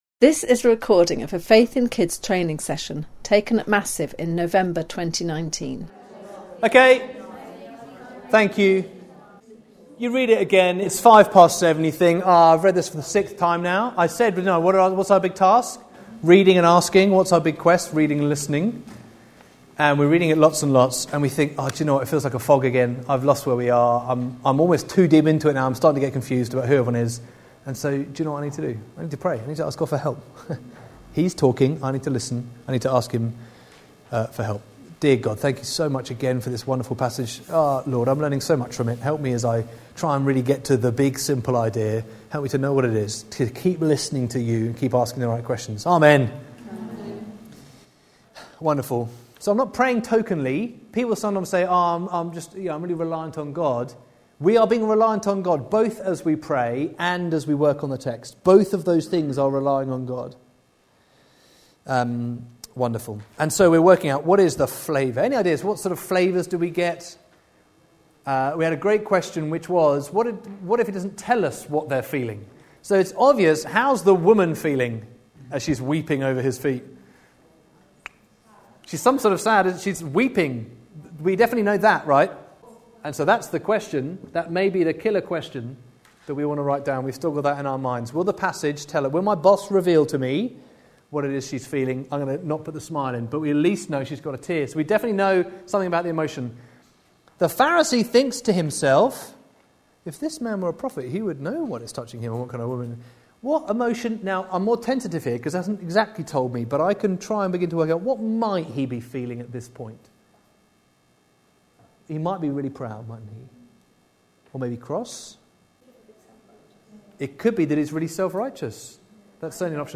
This seminar is the second part of our stream one series. (part 1 is titled: From text to big idea) An audio recording and handout of a practical workshop for those new to teaching in Sunday school.